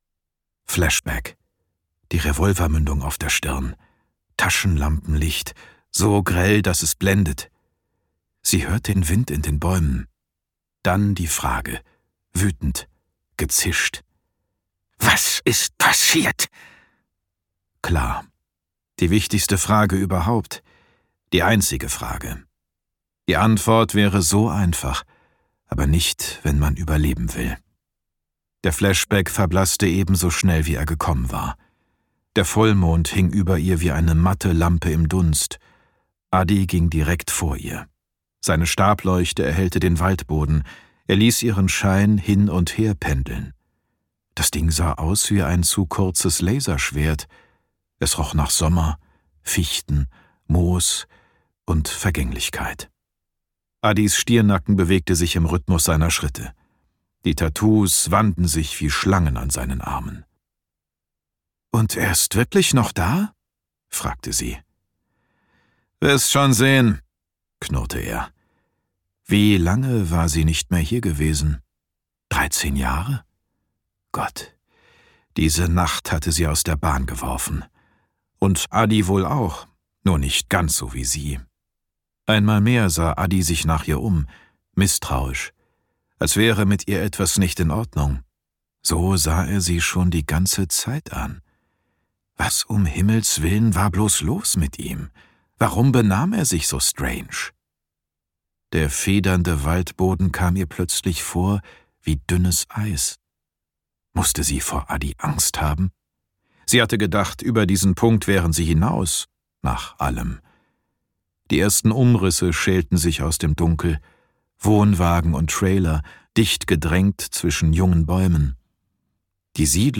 Genre Thriller
Details zum Hörbuch